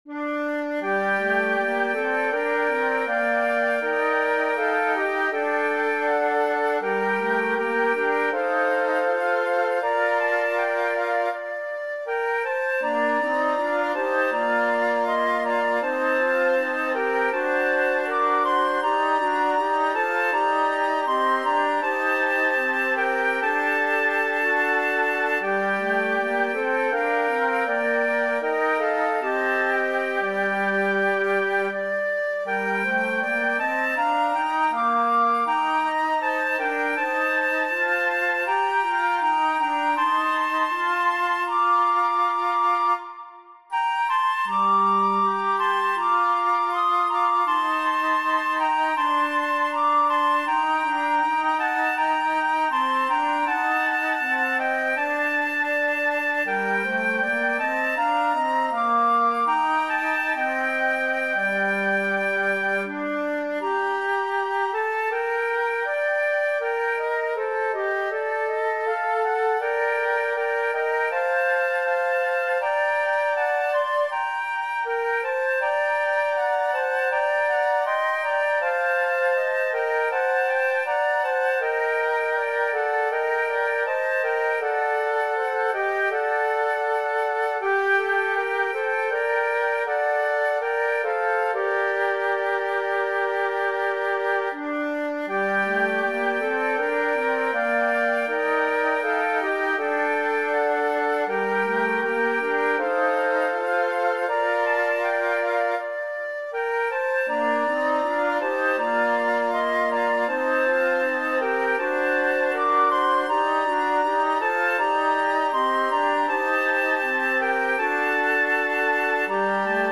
Besetzung: Flötenquintett
3 meditative Adventslieder für Flötenquartett